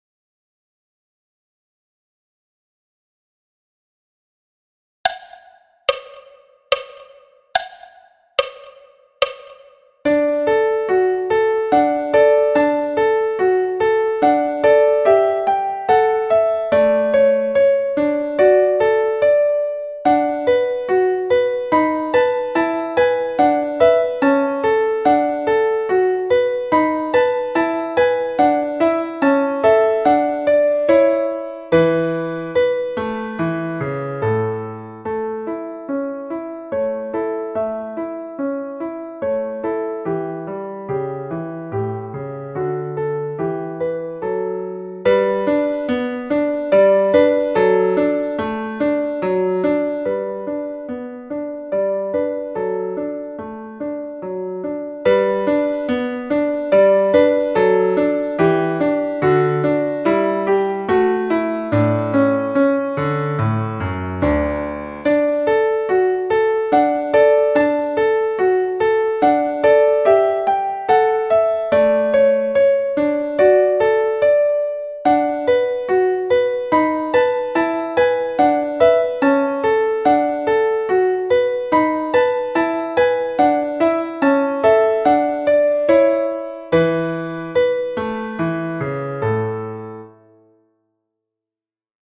Menuet de Mozart piano à 72 bpm
Menuet-de-Mozart-piano-a-72-bpm.mp3